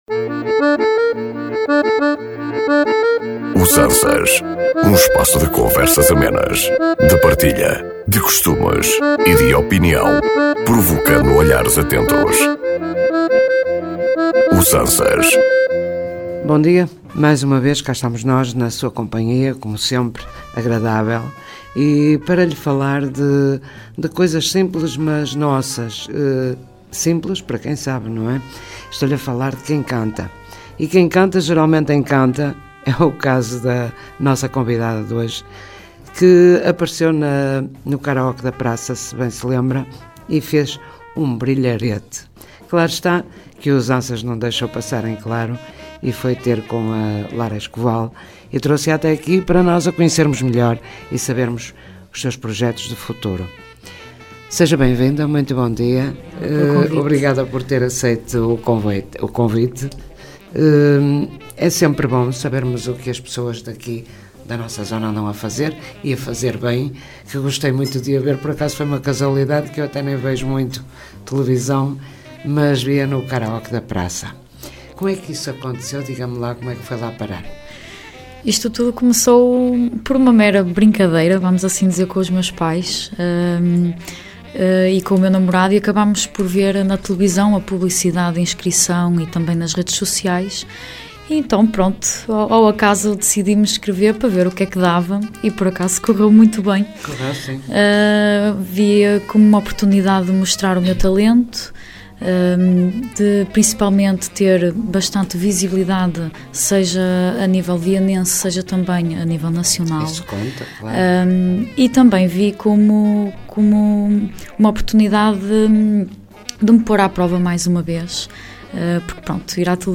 Um espaço de conversas amenas, de partilha, de costumes e de opinião, provocando olhares atentos.